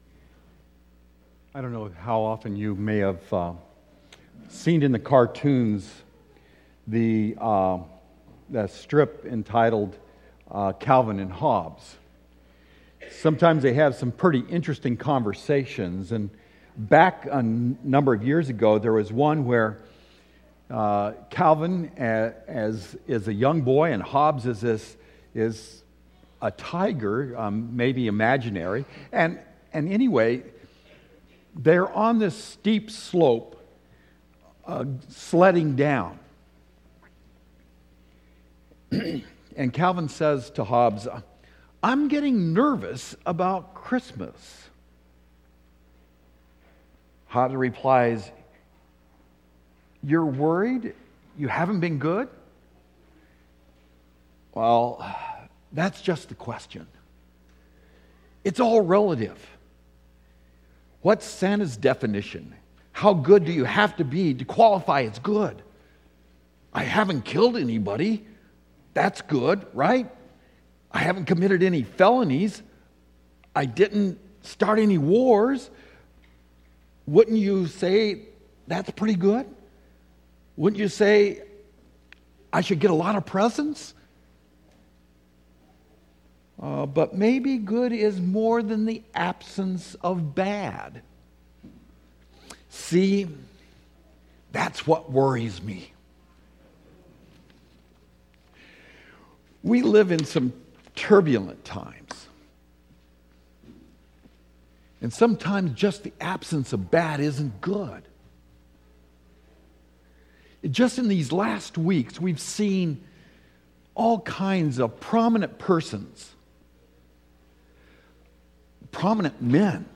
1st Sunday of Advent